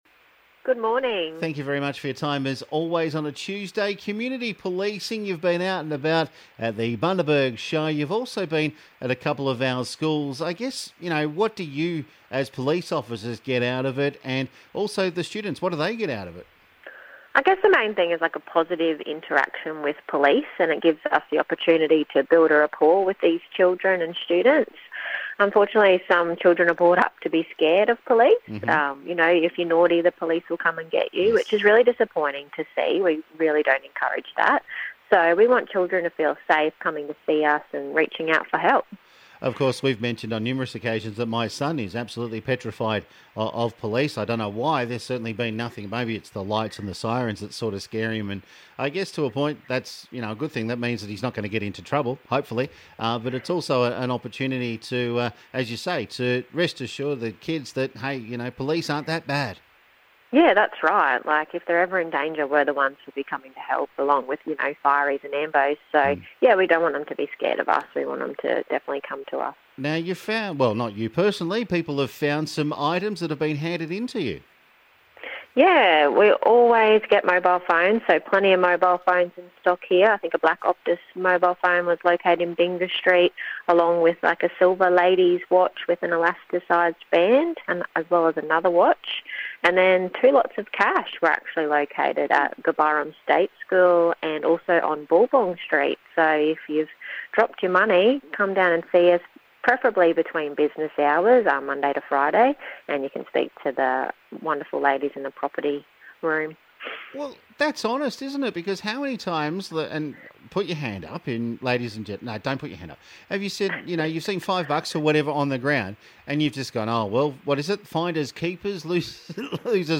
a chat about the upcoming seniors festival, community policing including visits to local schools and some items that have been found and handed in.